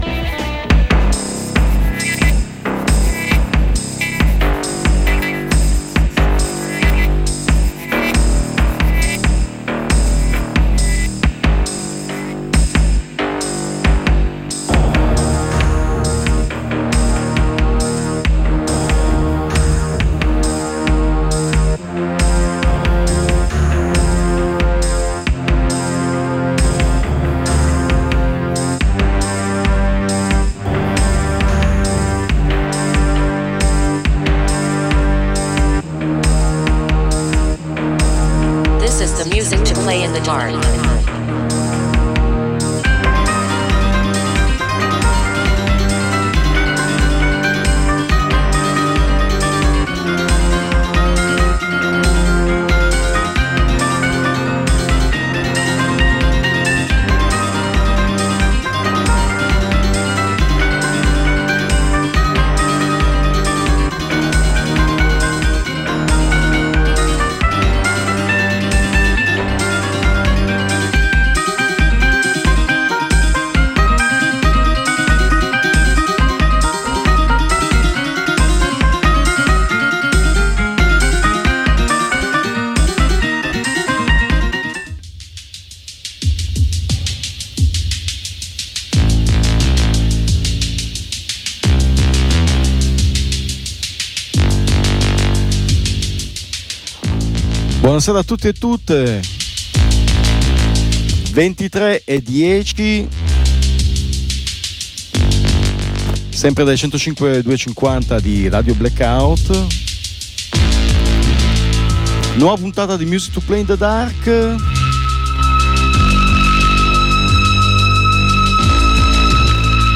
Per un’ora verrete condotti attraverso un percorso trasversale fatto da sonorità che non si fermano ad un genere: si può passare dall’industrial alla wave, facendo una fermata nel punk, nel death metal, nell’electro oppure anche nel math rock.